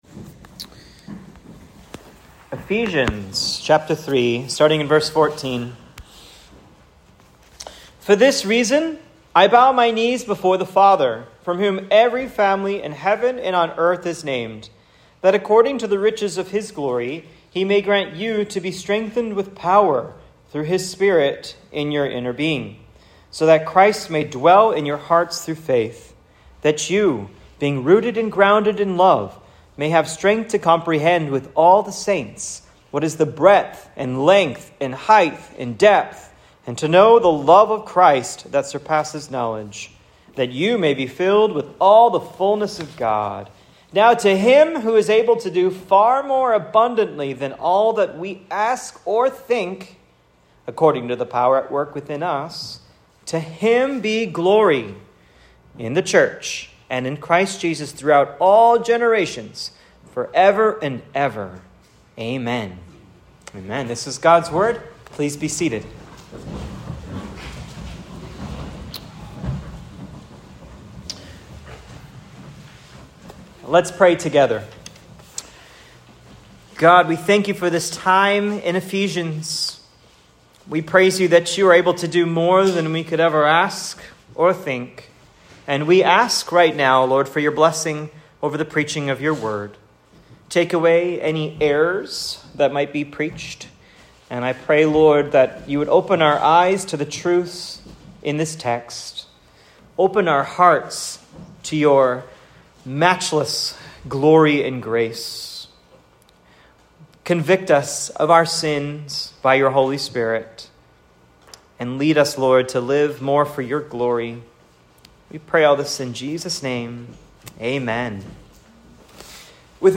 Eph 3:20-21 Sermon "To God be the Glory!"